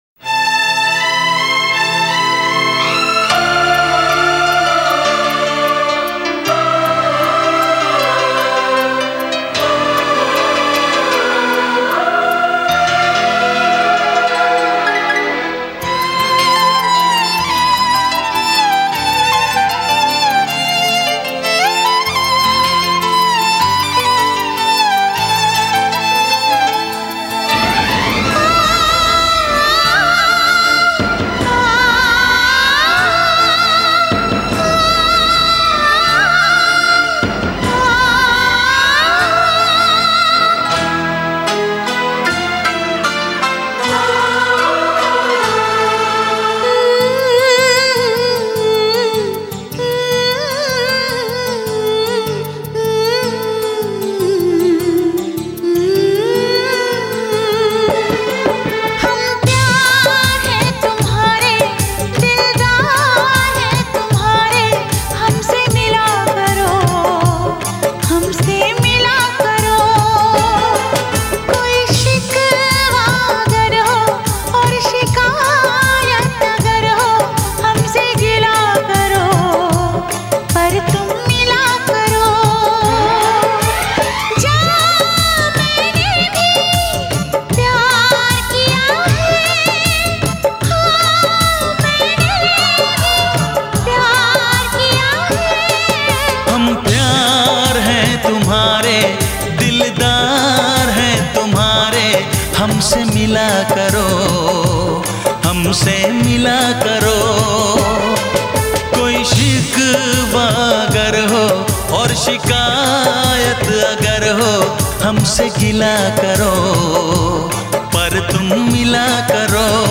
2. Bollywood MP3 Songs